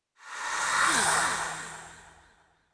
Xerah-Vox_Whisper.wav